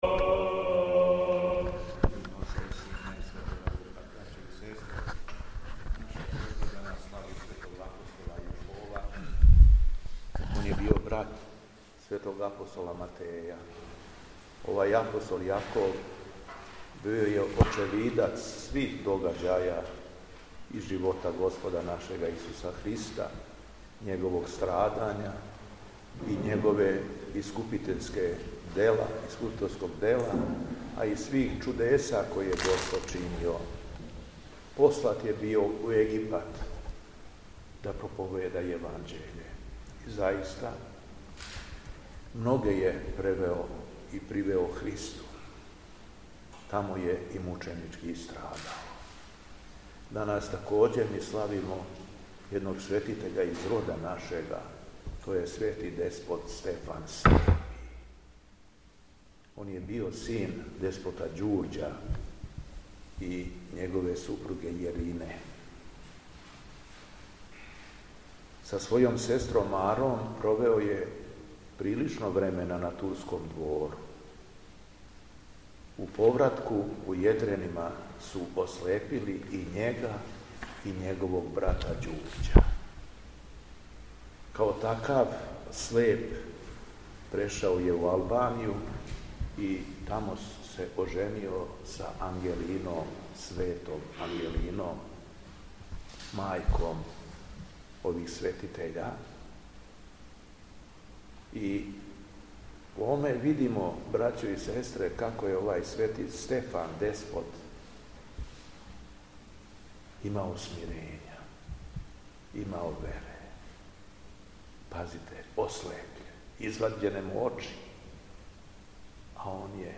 Беседа Његовог Високопреосвештенства Митрополита шумадијског г. Јована
Након прочитаног јеванђељског зачала верном народу се надахнутим беседом обратио Митрополит Јован: